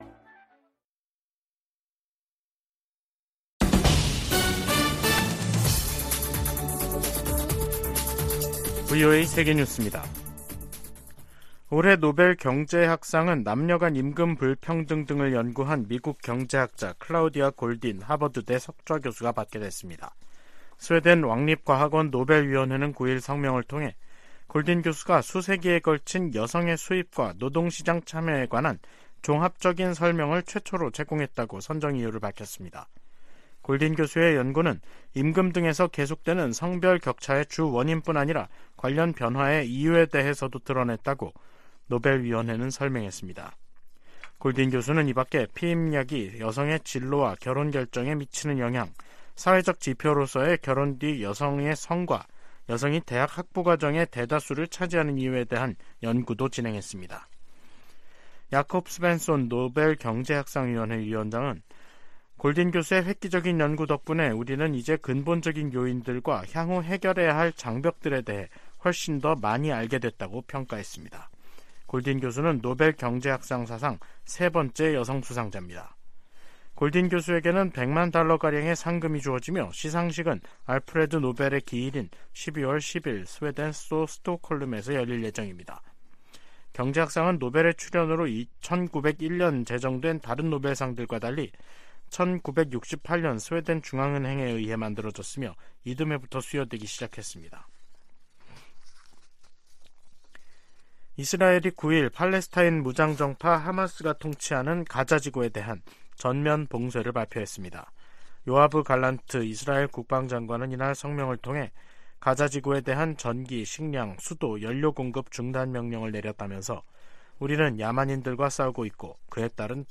VOA 한국어 간판 뉴스 프로그램 '뉴스 투데이', 2023년 10월 9일 3부 방송입니다. 토니 블링컨 미 국무장관은 최근 한국에서 동결 해제된 이란 자금이 이스라엘 공격에 지원됐다는 일각의 주장을 일축했습니다. 한국에선 동북아 안보에 관한 미국의 집중도 하락, 북한이 중동의 전황을 반미연대 확대로 활용할 가능성 등이 제기되고 있습니다. 유럽연합(EU)은 러시아로 북한의 대포가 이전되기 시작했다는 보도에 관해 양국 무기 거래 중단을 촉구했습니다.